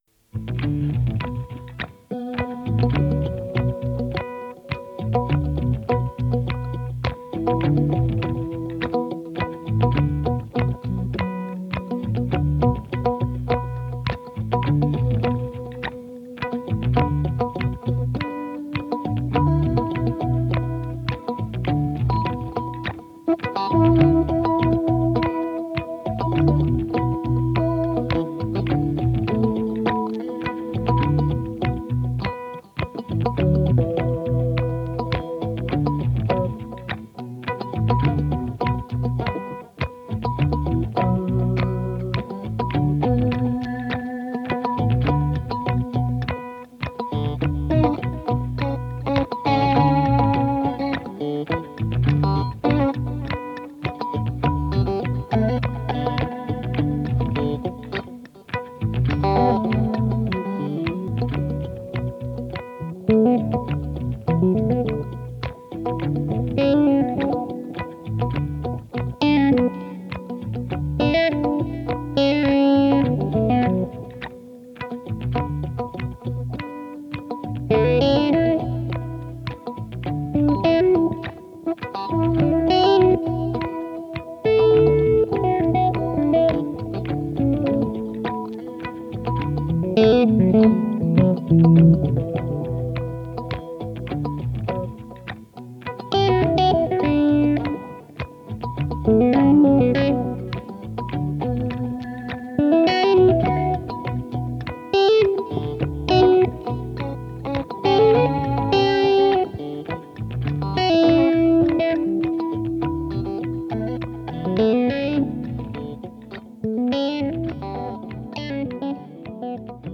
Pretty groovy!
Der Groove macht gute Laune.
It’s a very laid back groove with a precious late night feeling …